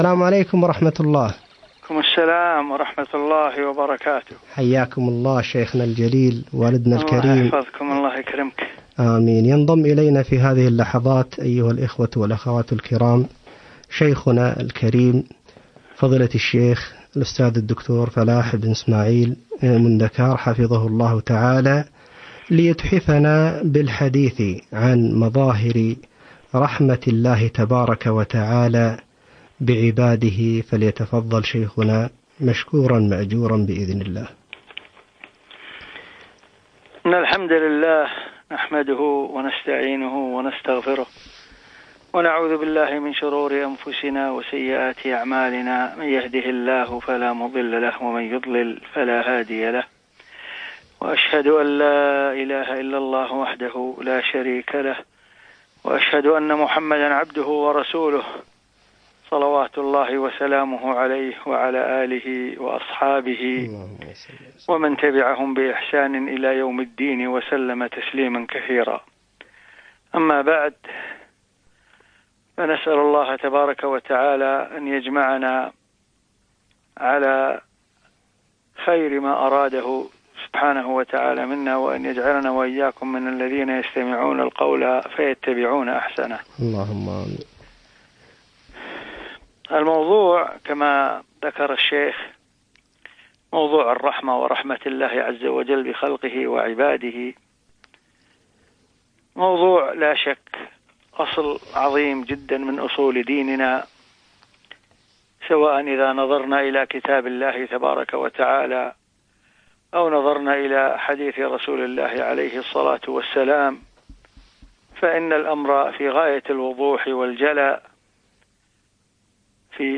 مداخلة هاتفية عن رحمة الله تعالى - إذاعة القرآن الكريم